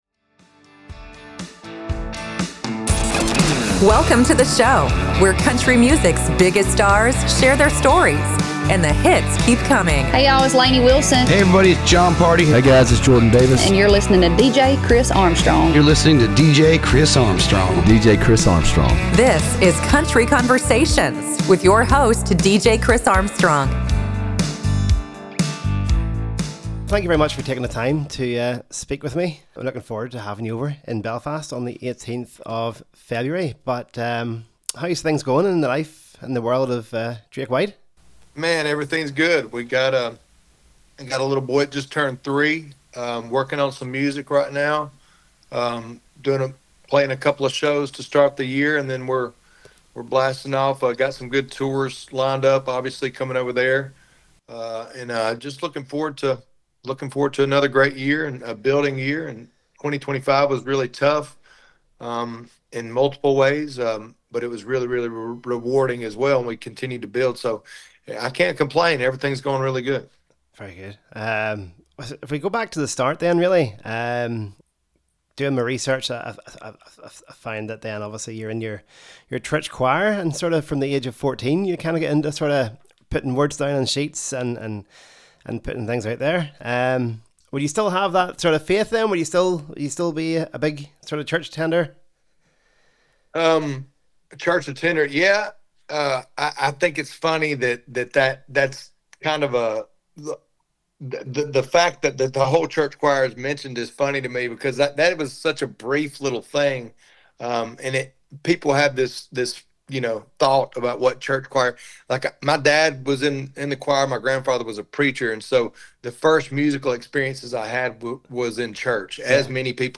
in Conversation with Drake White